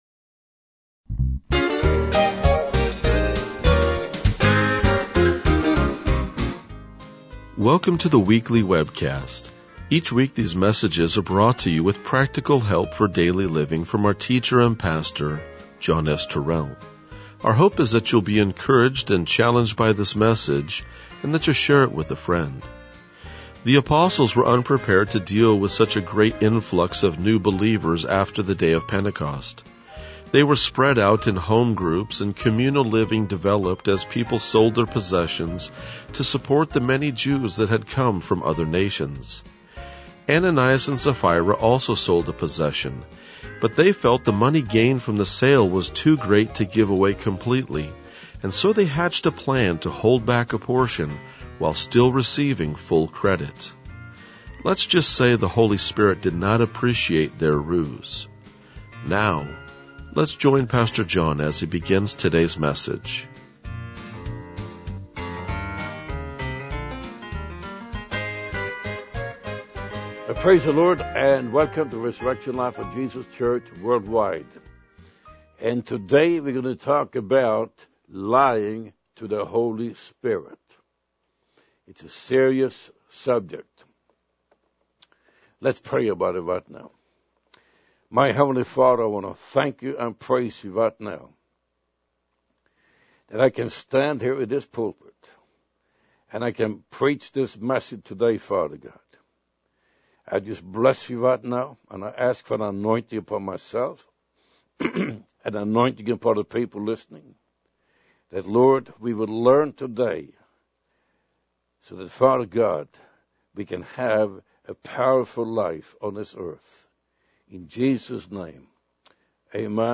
RLJ-2002-Sermon.mp3